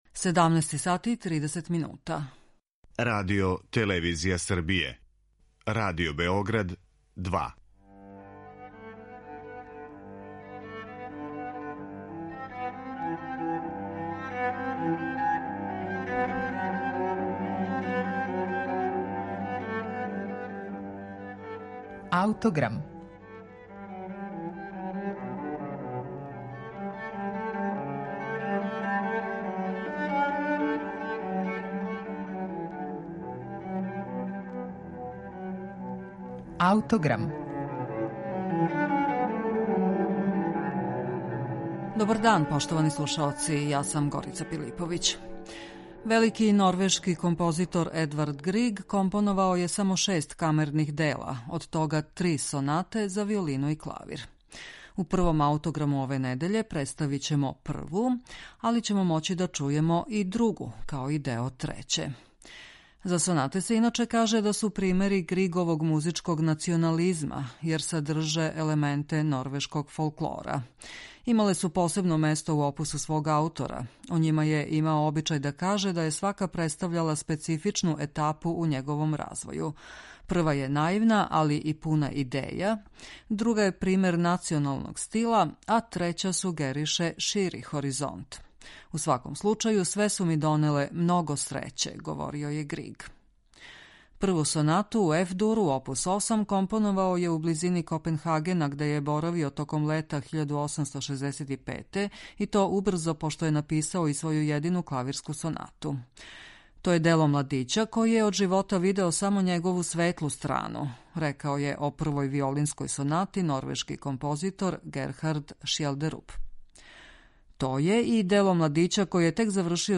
Грига је инспирисао и Оле Бул, велики норвешки виолиниста тог времена који је неколико година раније на младог композитора оставио такав утисак да је овај пожелео да компонује сонату за виолину и клавир.